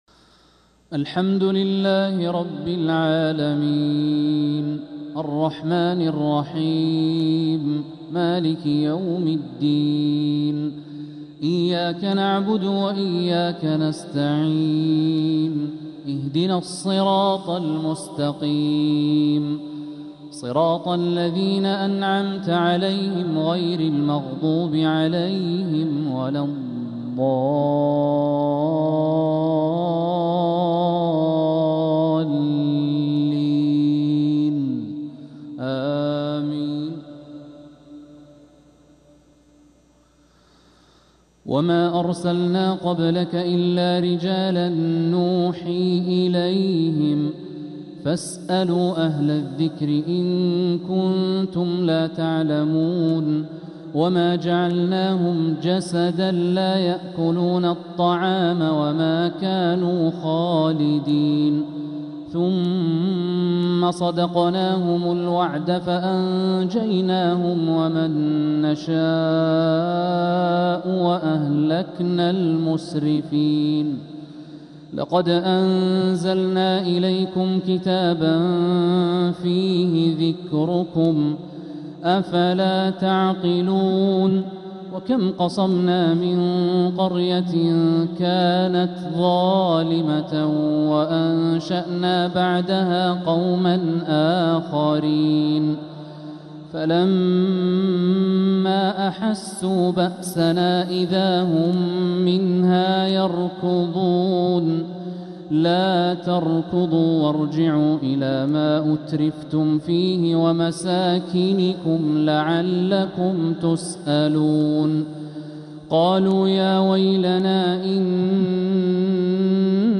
عشاء الثلاثاء 6 محرم 1447هـ من سورة الأنبياء 7-20 | Isha prayer from Surat Al-Anbiya 1-7-2025 > 1447 🕋 > الفروض - تلاوات الحرمين